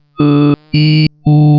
neanderthal_aiu.wav